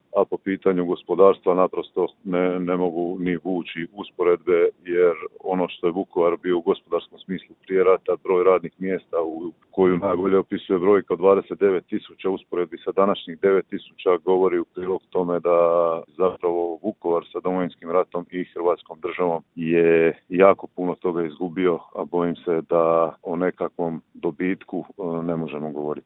ZAGREB - Uoči obilježavanja vukovarske tragedije kratko smo razgovarali s gradonačelnikom Vukovara Ivanom Penavom.